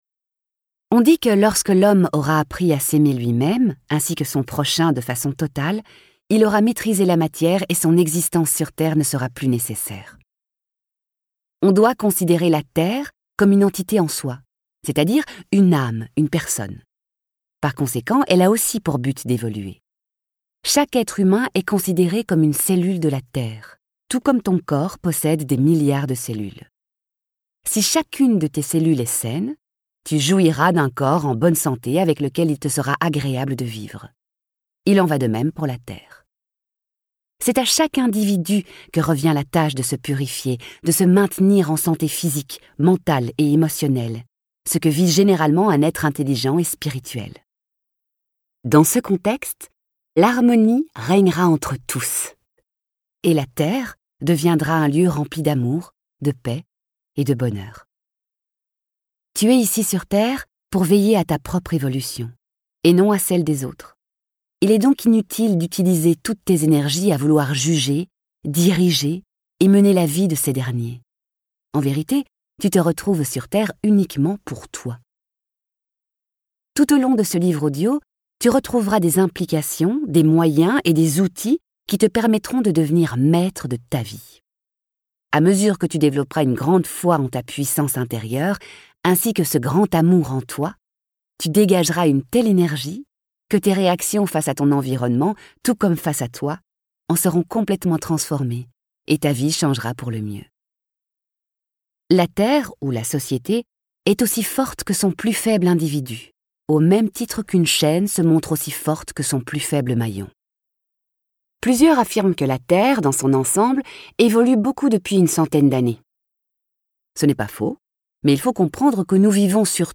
livre audio